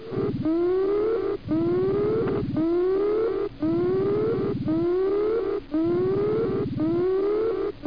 ALARM_4.mp3